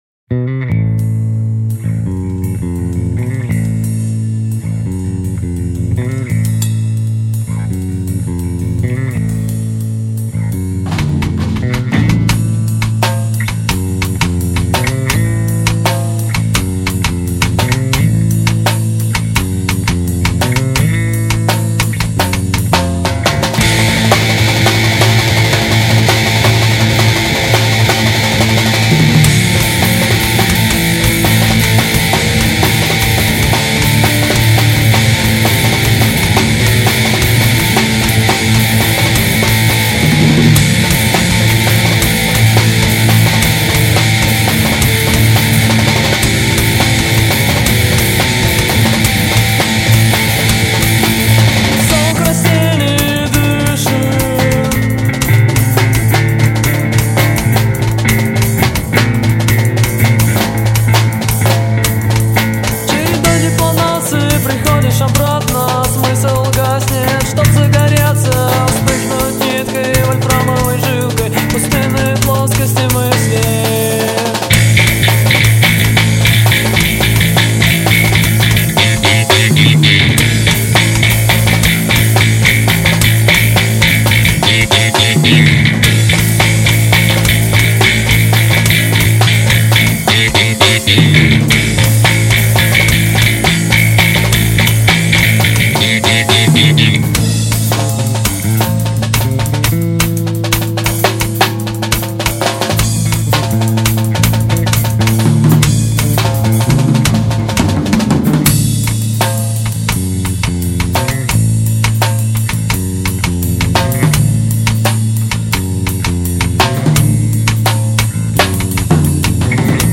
бас-гитара, вокал, дудка